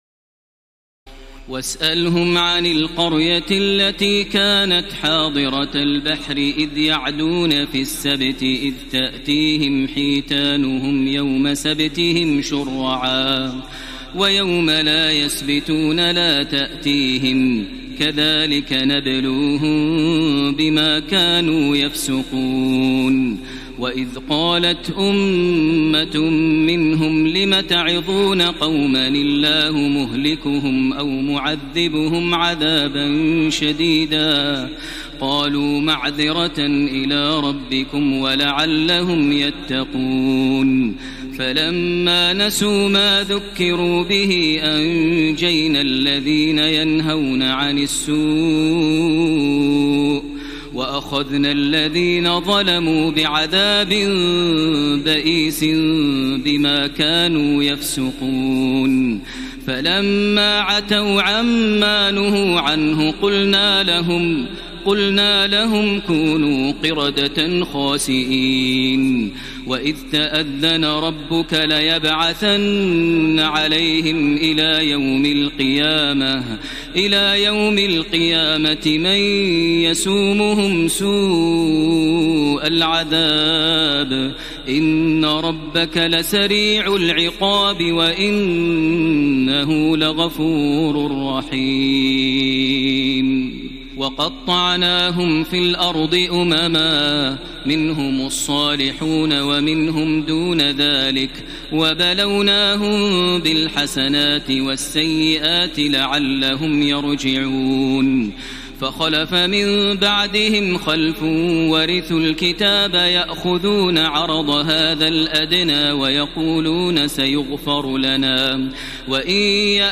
تراويح الليلة الثامنة رمضان 1433هـ من سورتي الأعراف (163-206) والأنفال (1-40) Taraweeh 8 st night Ramadan 1433H from Surah Al-A’raf and Al-Anfal > تراويح الحرم المكي عام 1433 🕋 > التراويح - تلاوات الحرمين